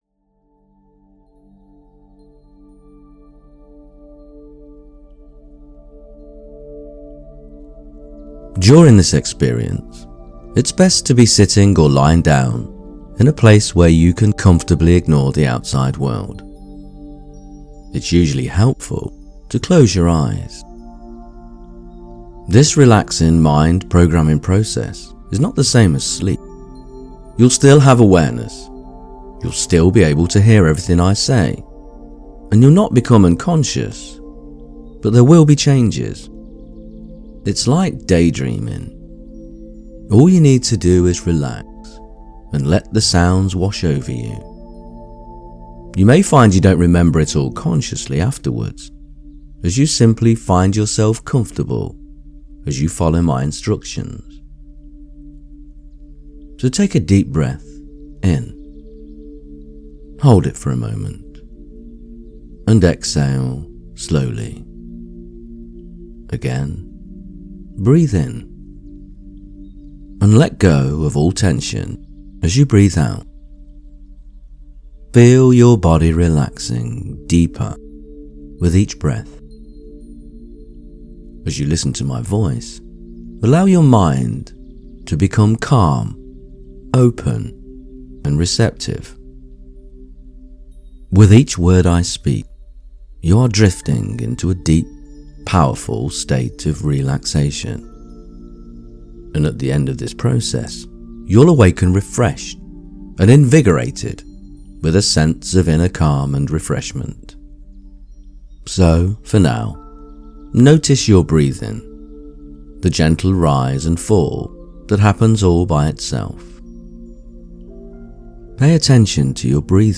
Whether you’ve lost someone you love, a version of yourself, or a part of your life — this guided meditation helps you carry it with more grace, more peace, and more love.
Grief-manifesting-meditation.mp3